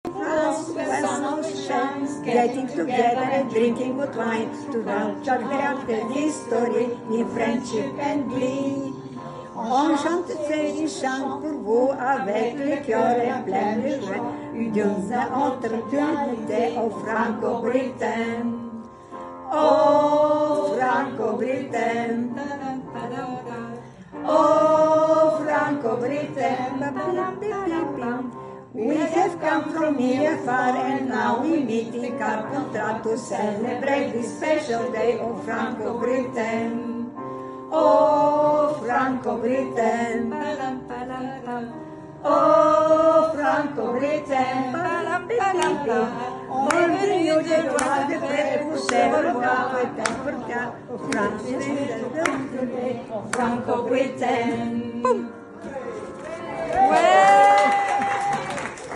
chant de l’hymne franco-britannique qui a été créé pour cet anniversaire